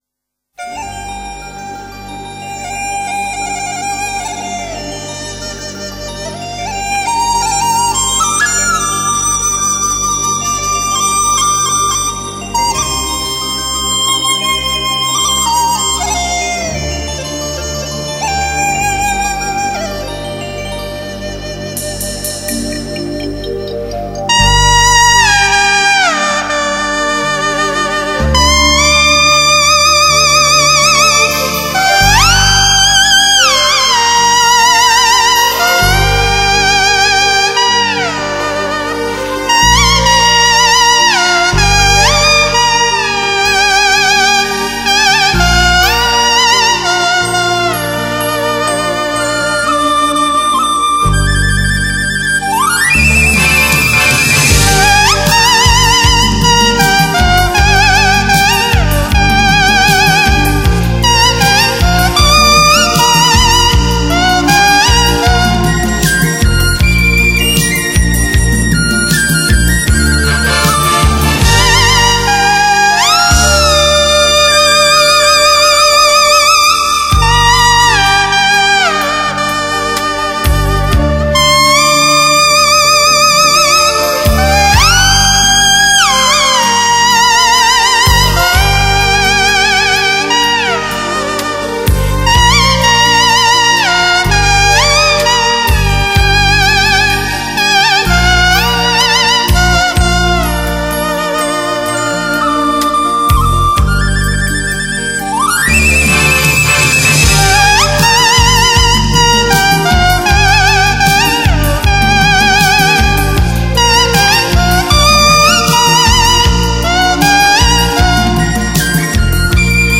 音色优美、音质纯厚